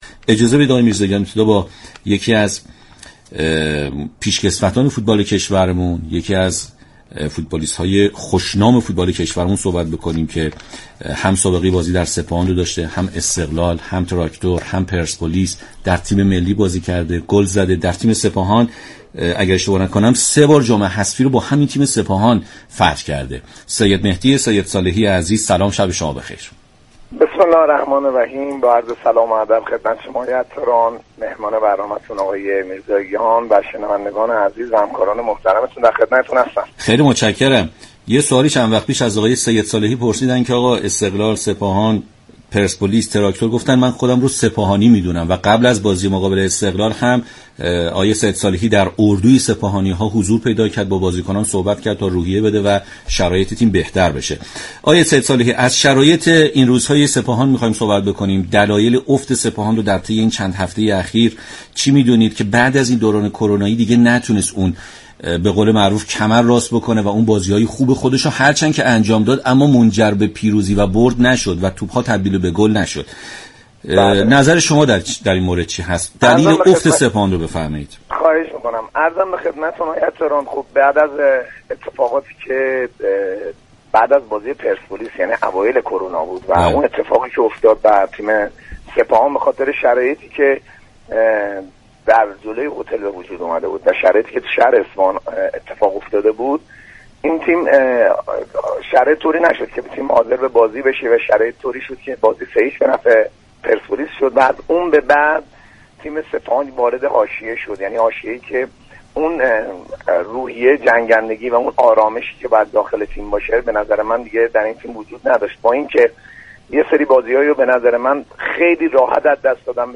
شما می توانید از طریق فایل صوتی شنونده ادامه این گفتگو باشید.